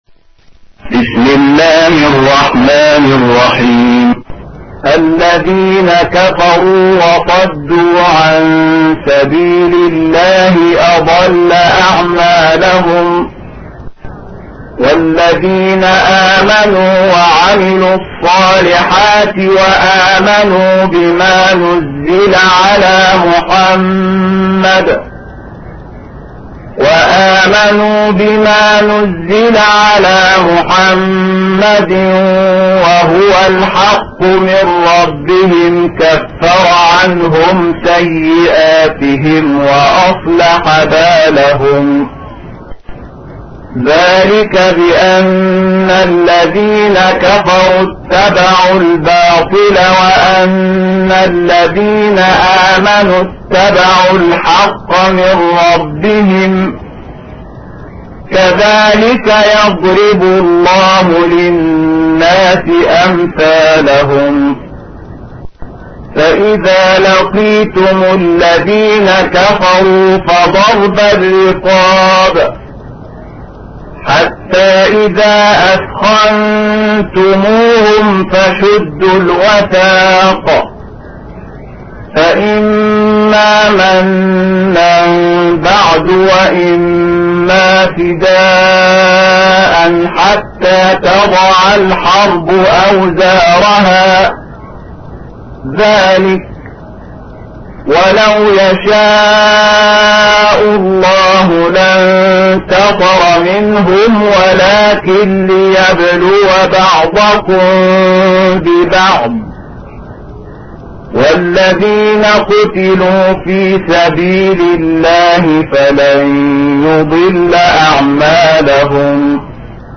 تحميل : 47. سورة محمد / القارئ شحات محمد انور / القرآن الكريم / موقع يا حسين